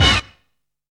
JIVE STAB.wav